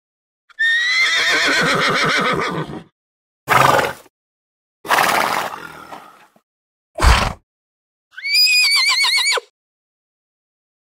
جلوه های صوتی
دانلود صدای شیهه اسب 1 از ساعد نیوز با لینک مستقیم و کیفیت بالا